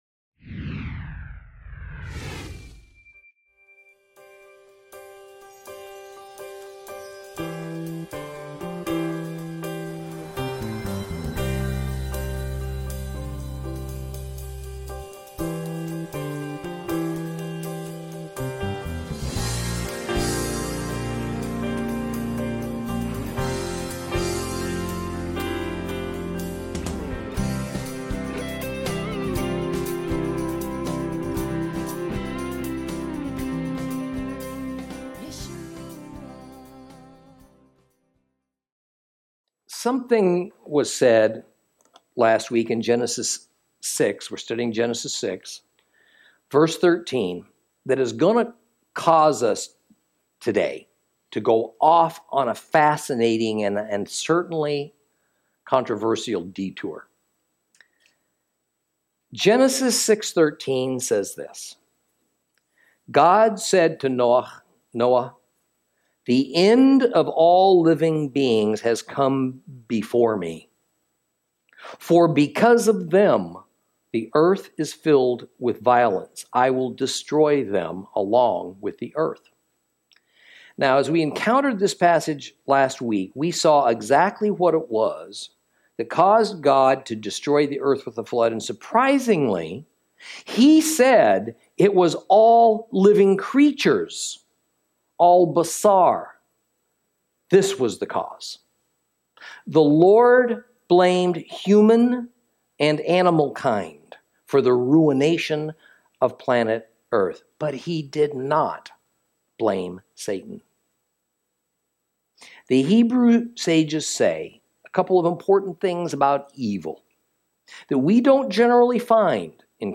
Lesson 6 Ch6 - Torah Class